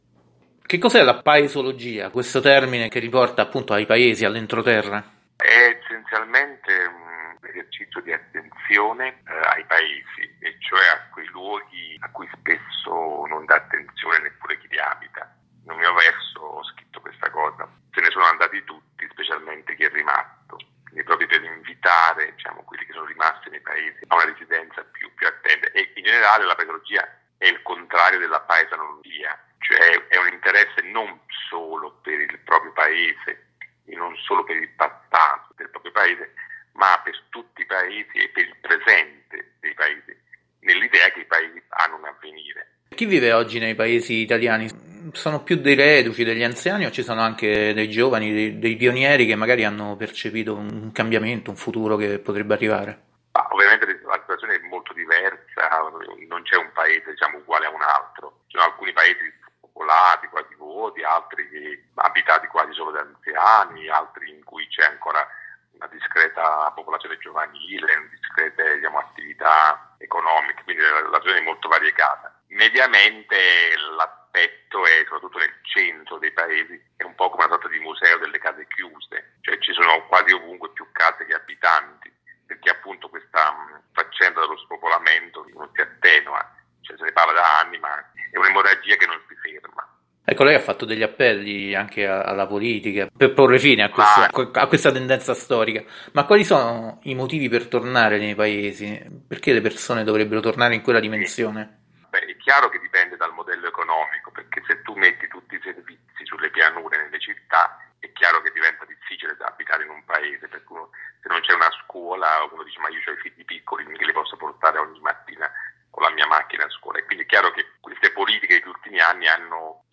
Ecosistema Interviste
Versione integrale dell’intervista trasmessa da Radio Vaticana Italia nel programma “Il Mondo alla Radio” dell’8 aprile 2021.